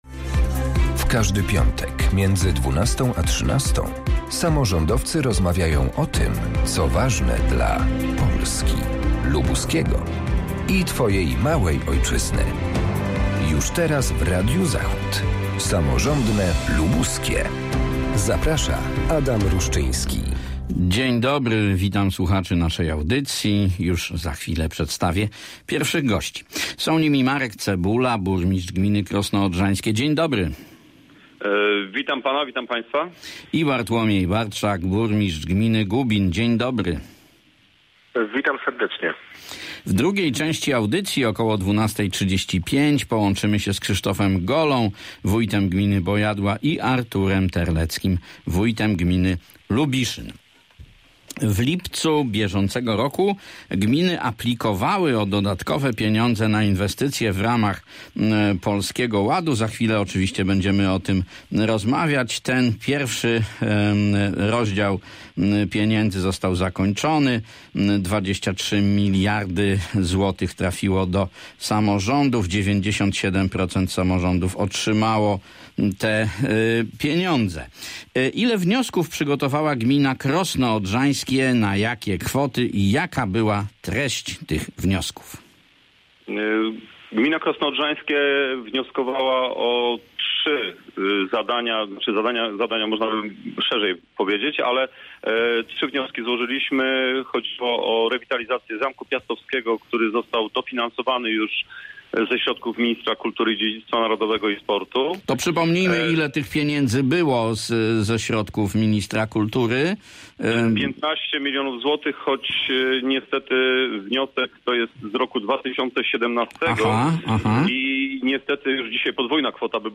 W audycji rozmawiamy o pozyskanych pieniądzach na inwestycje w ramach programu Polski Ład i o ograniczaniu niskiej emisji C02. Moimi gośćmi są: Marek Cebula – burmistrz gminy Krosno Odrzańskie, Bartłomiej Bartczak – burmistrz Gubina, Krzysztof Gola – wójt gminy Kolsko i Artur Terlecki – wójt gminy Lubiszyn.